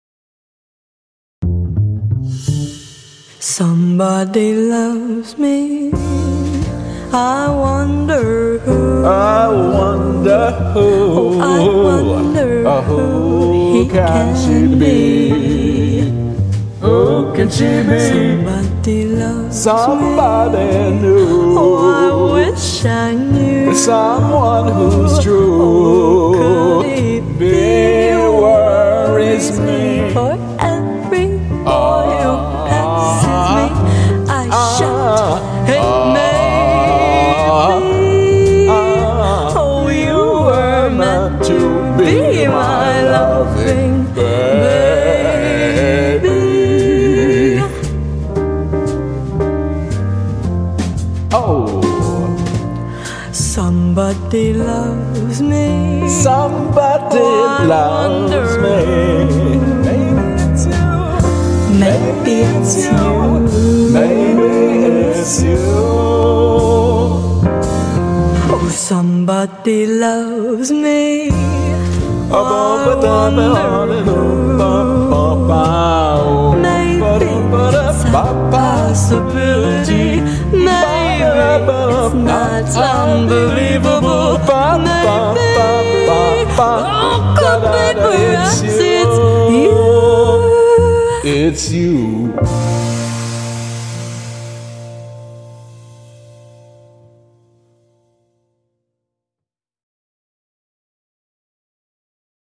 vocals
(And she has a great jazz band accompanying her.)